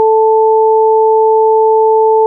octava.mp3